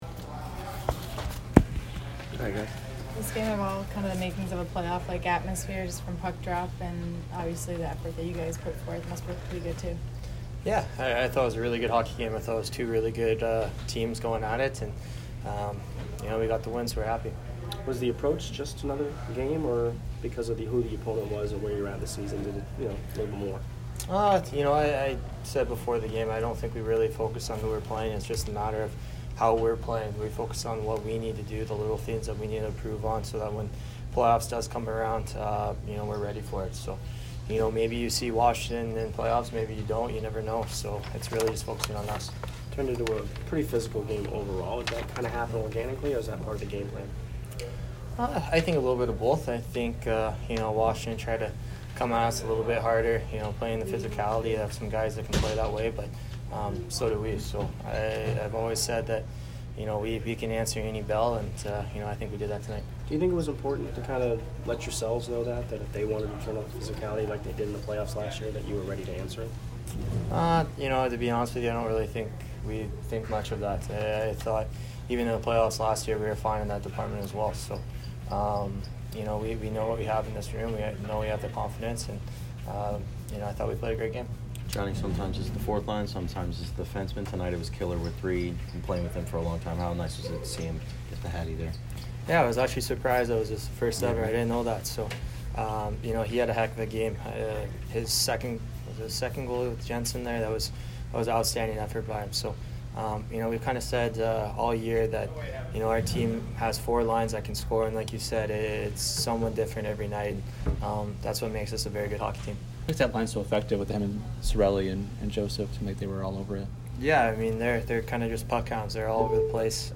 Tyler Johnson post-game 3/16